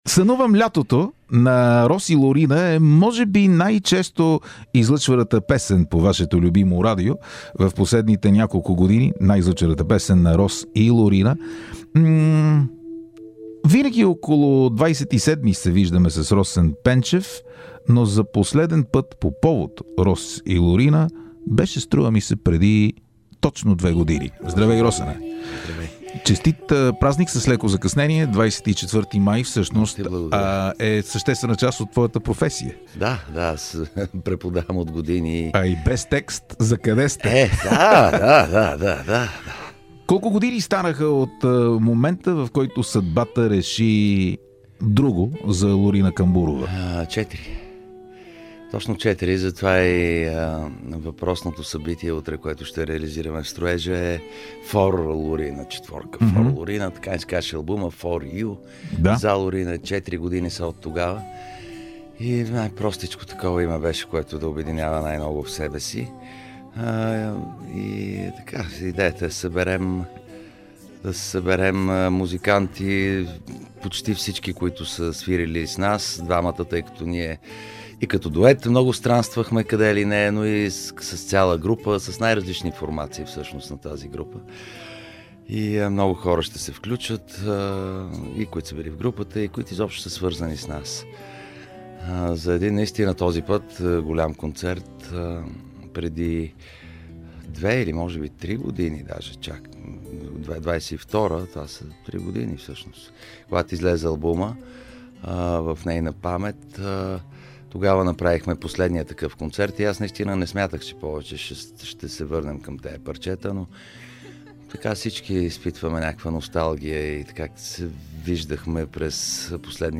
едно интервю